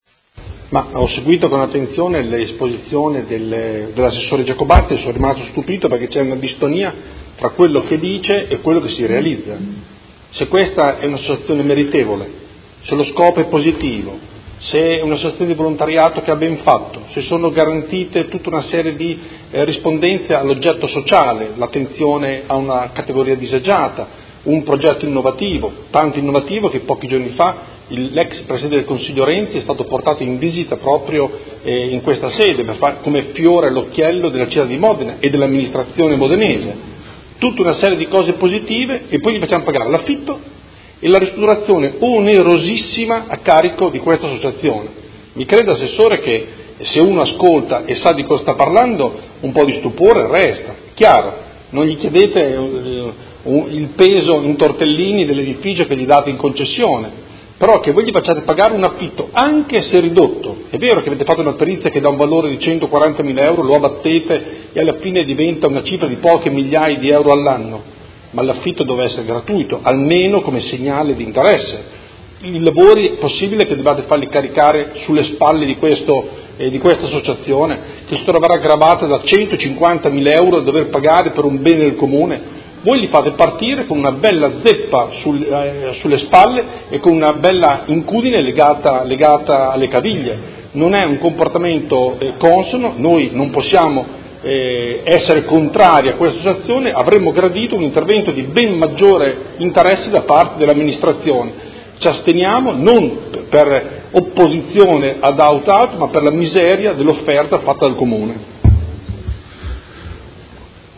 Seduta del 23/11/2017 Dibattito. Concessione in diritto di superficie a titolo oneroso a favore dell’Associazione Il Tortellante APS di una porzione immobiliare posta a Modena all’interno del comparto denominato “Ex MOI di Via C. Menotti” – Approvazione